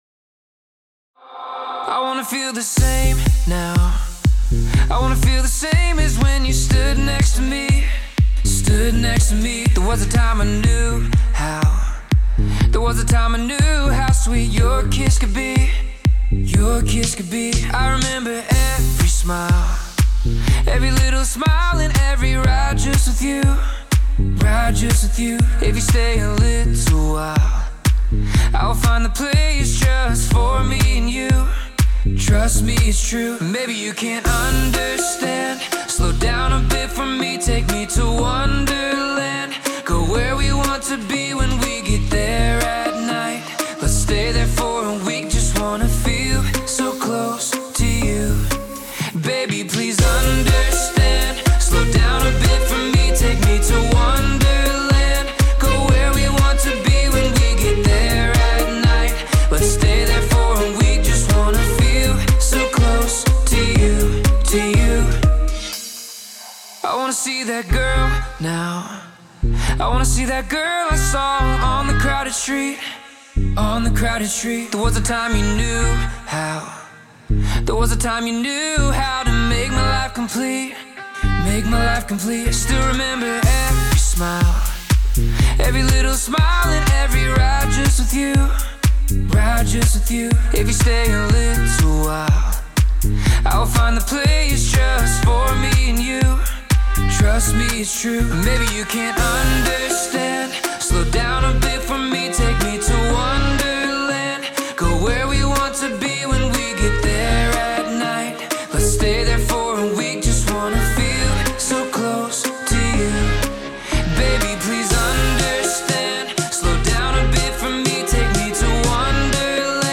это зажигательная танцевальная композиция в жанре EDM